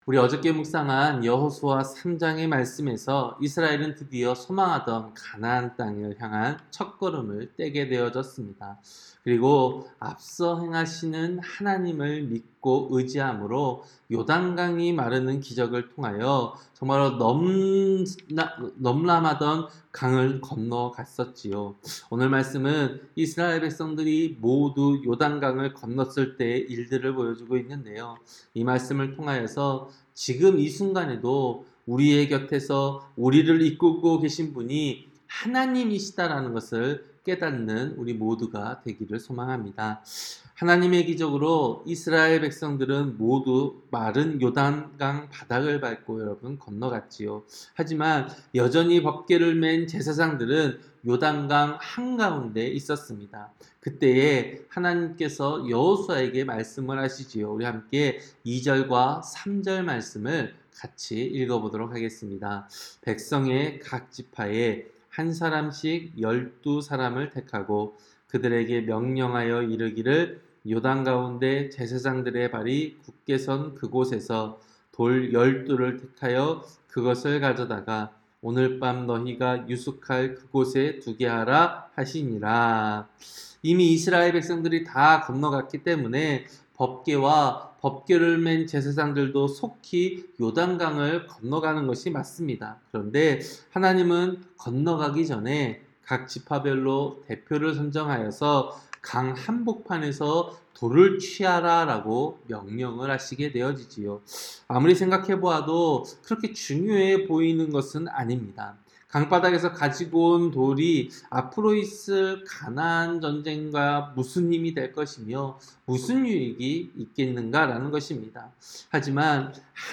새벽설교-여호수아 4장